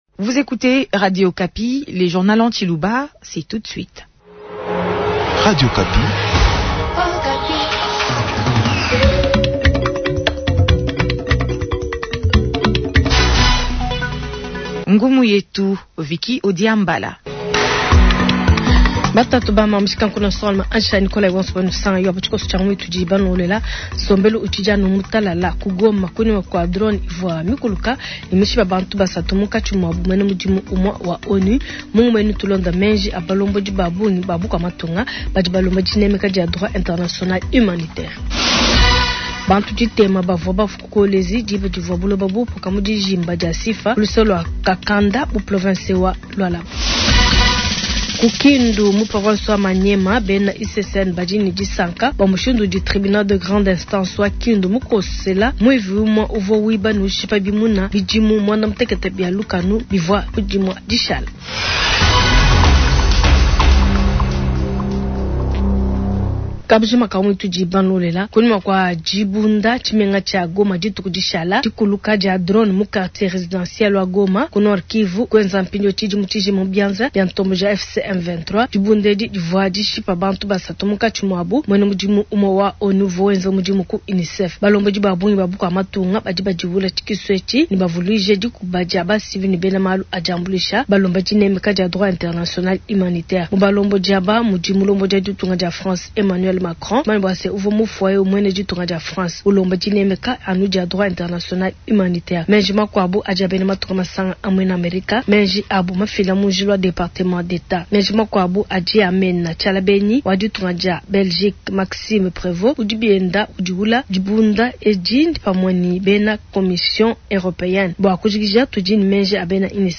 Journal matin